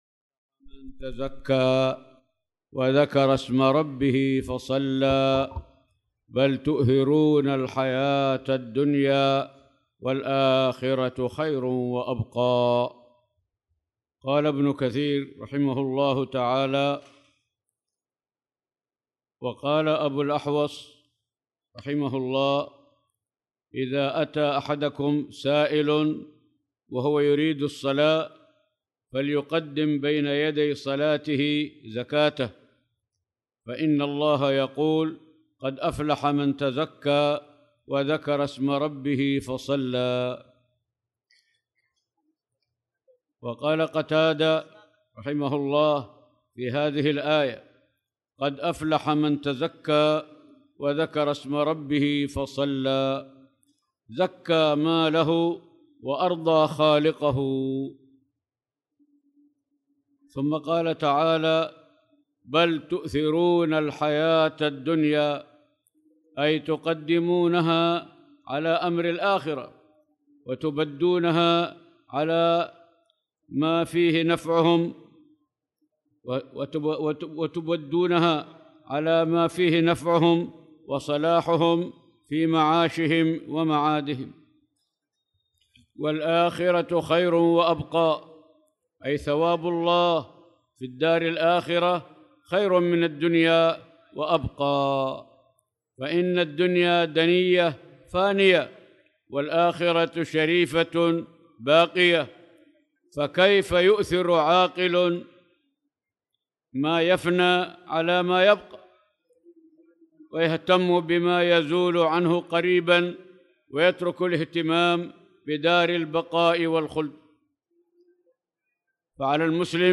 تاريخ النشر ٢١ شعبان ١٤٣٧ هـ المكان: المسجد الحرام الشيخ